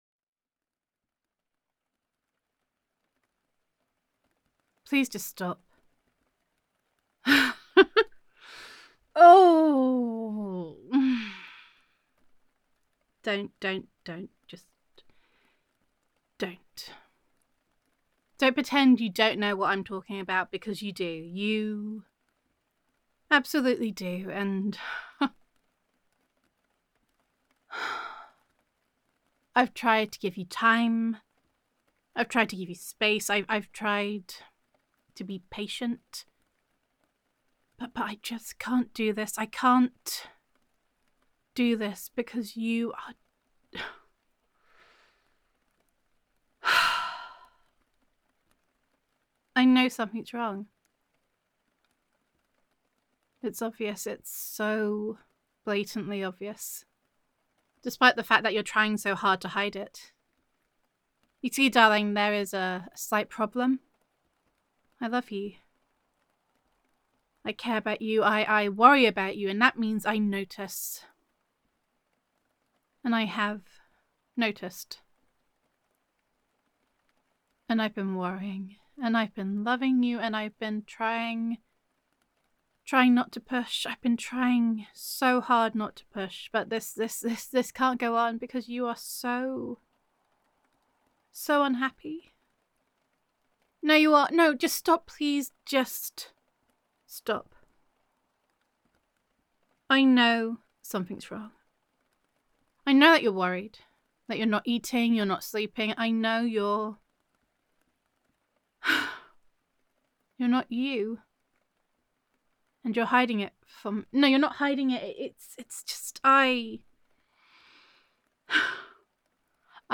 [F4A] My Werewolf Ate My Biscuits [Girlfriend Roleplay]